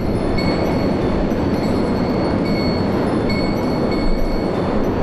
abjuration-magic-sign-rune-loop.ogg